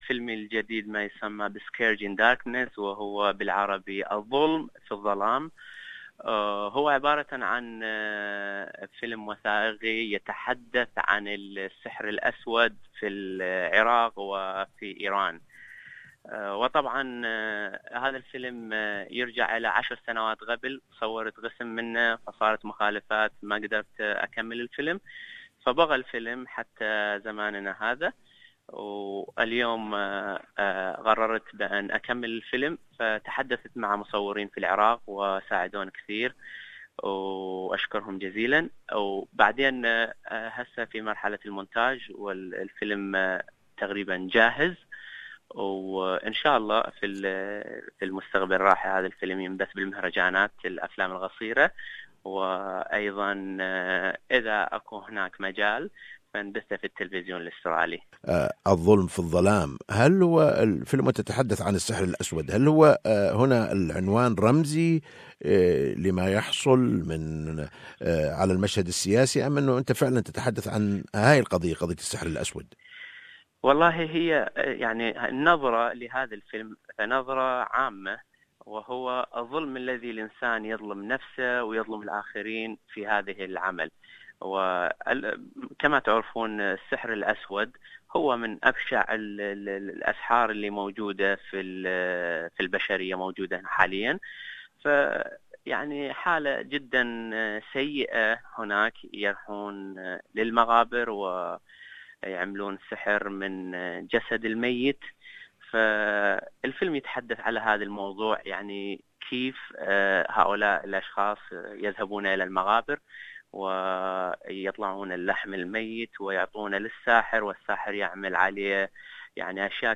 speaks to SBS Arabic 24 about his new documentary